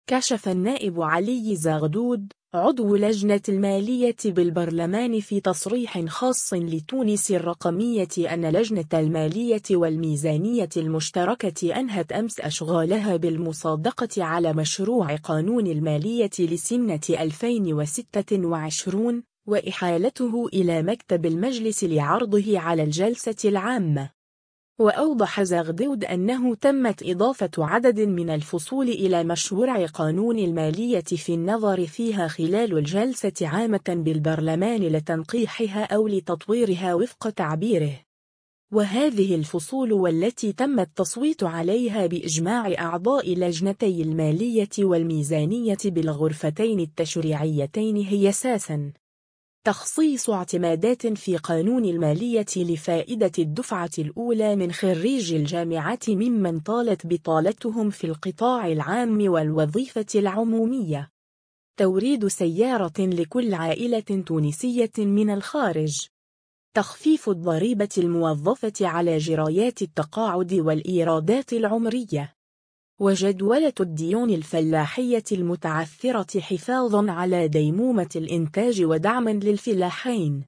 كشف النائب علي زغدود،عضو لجنة المالية بالبرلمان في تصريح خاص لـ”تونس الرقمية” أن لجنة المالية والميزانية المشتركة أنهت أمس أشغالها بالمصادقة على مشروع قانون المالية لسنة 2026، وإحالته إلى مكتب المجلس لعرضه على الجلسة العامة.